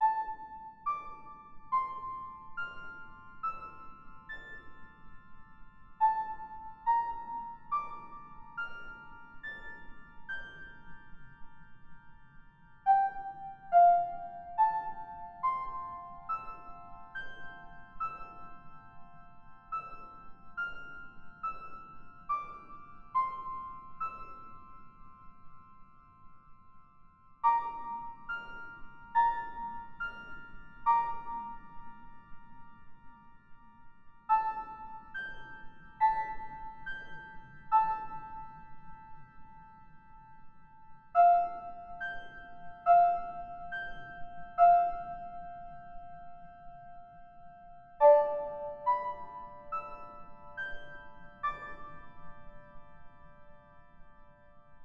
Add ambient music which changes based on pill level